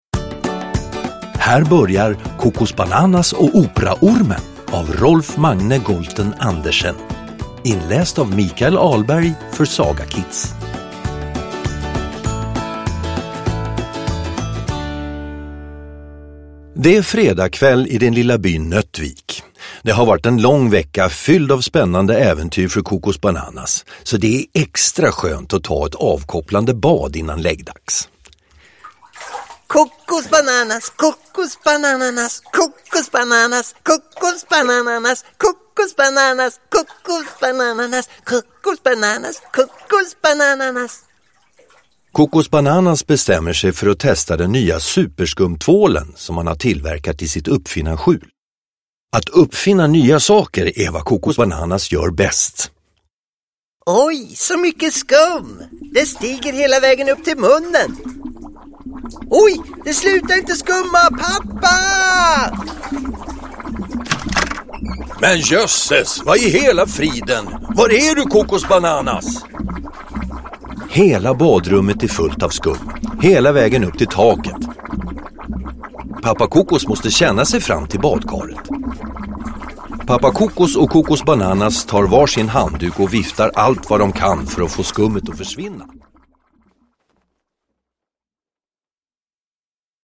Kokosbananas och operaormen – Ljudbok
Med härliga ljudeffekter och musik bjuder Kokosbananas på underhållning för hela familjen!